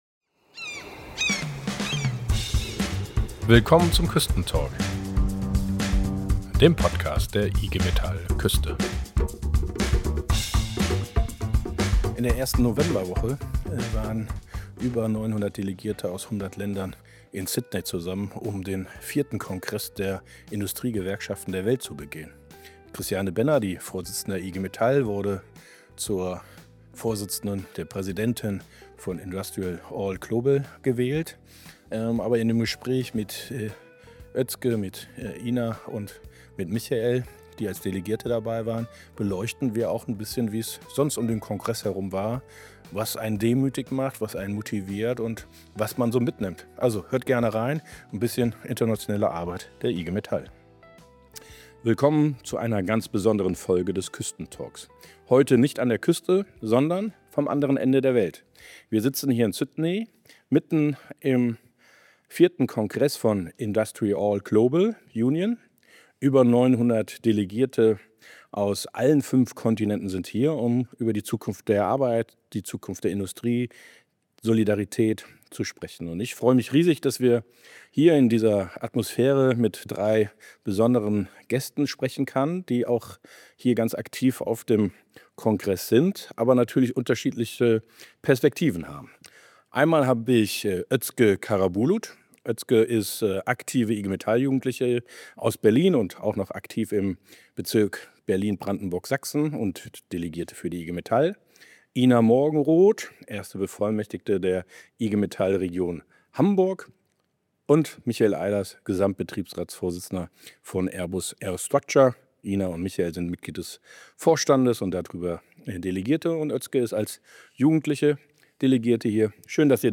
#51 live aus Sydney - Gewerkschaft global ~ KÜSTENTALK Podcast